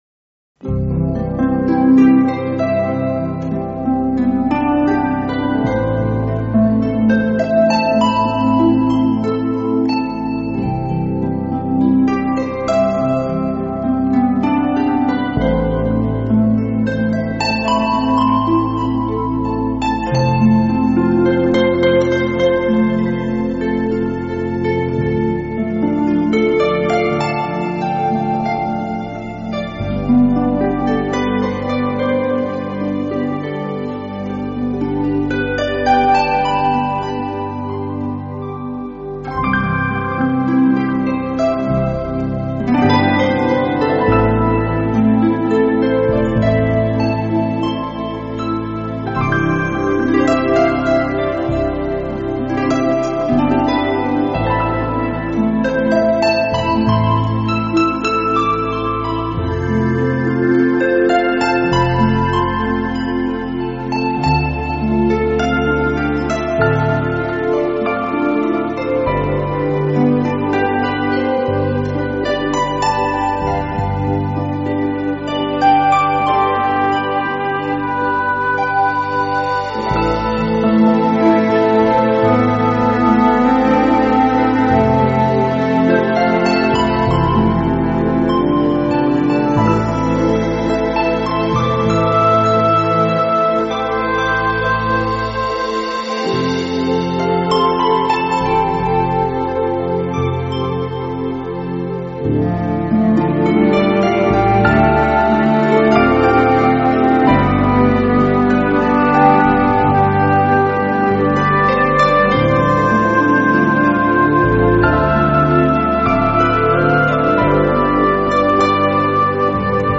Recording Mode: Stereo
Recording Type: Studio